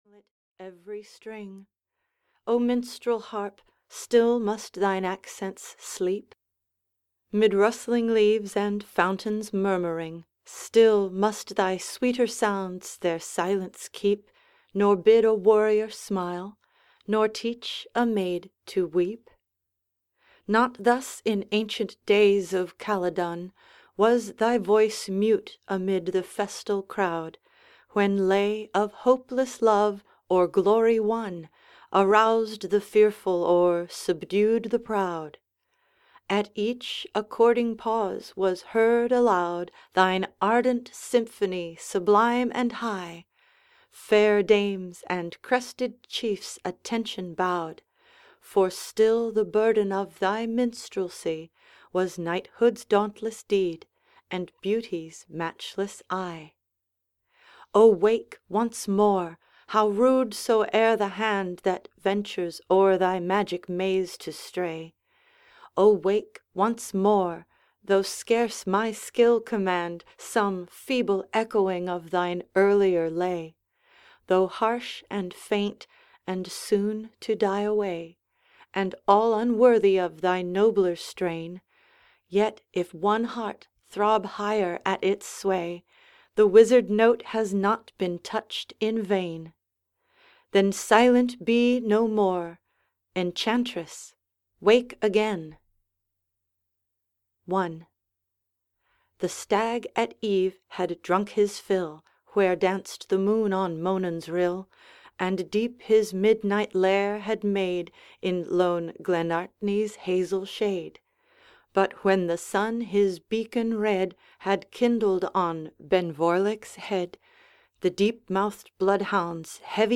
The Lady of the Lake (EN) audiokniha
Ukázka z knihy